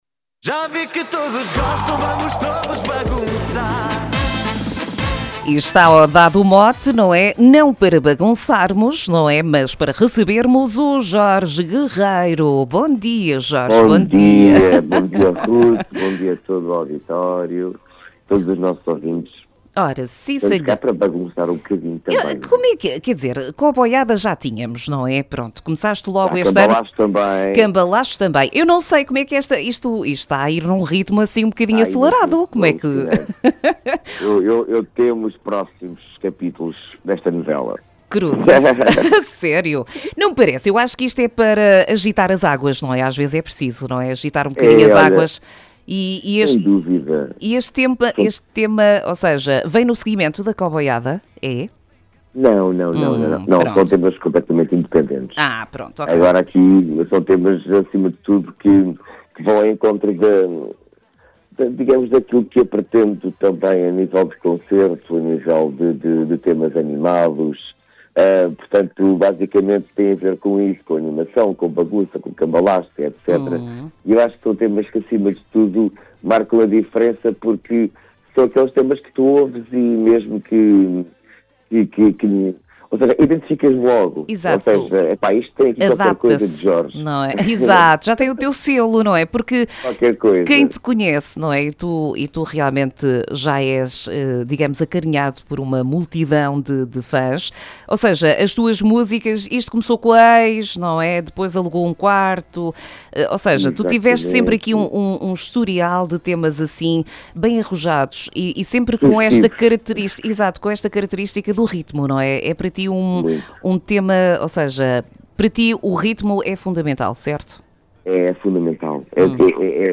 Entrevista
em direto no programa Manhãs NoAr